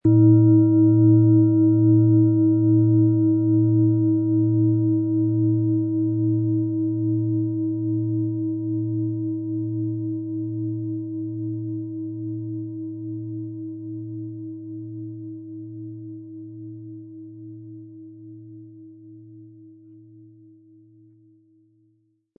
Planetenton 1 Planetenton 2
• Mittlerer Ton: Alphawelle
PlanetentöneThetawelle & Alphawelle
HerstellungIn Handarbeit getrieben
MaterialBronze